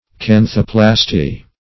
Search Result for " canthoplasty" : The Collaborative International Dictionary of English v.0.48: Canthoplasty \Can"tho*plas`ty\, n. [Gr.?, corner of the eye + ? to from.]